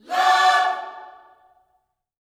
LOVECHORD2.wav